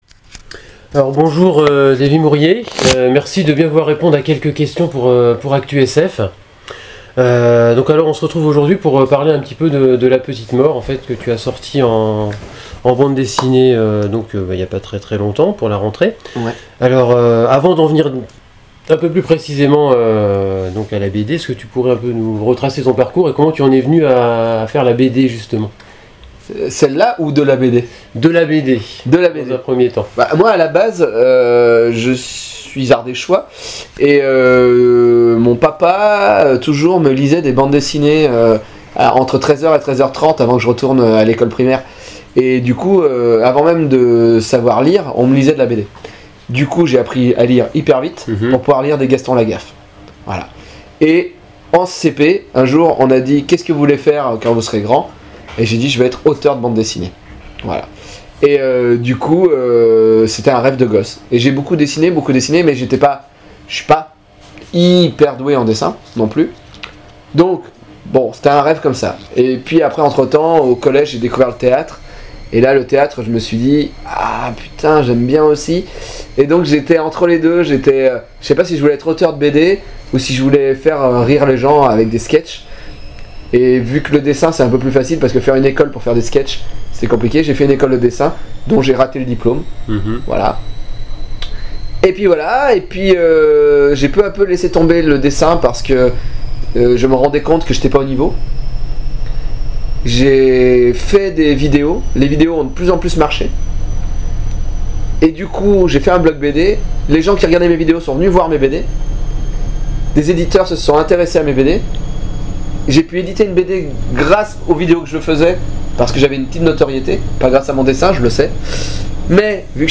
Interview Davy Mourier